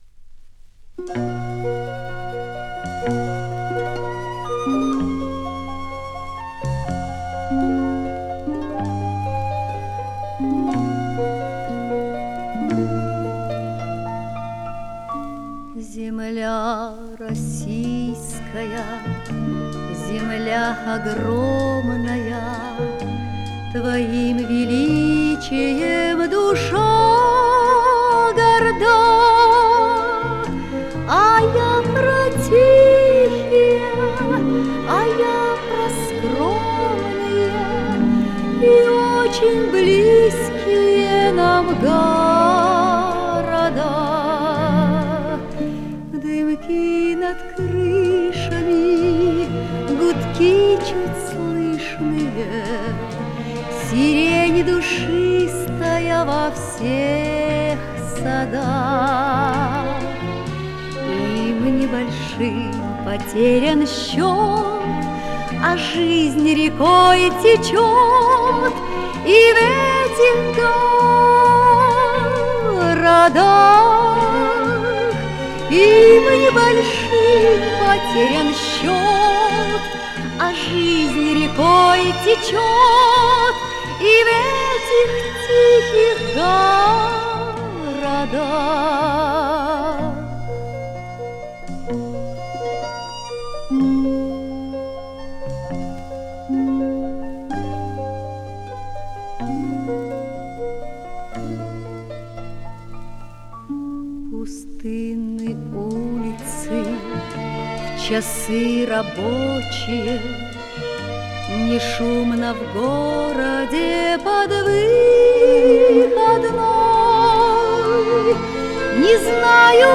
А голос несколько молодой и задорный для такого возраста.
Песня с пластинки загружена.
Спасибо! Да, разницы нет, на пластинке версия саундтрека.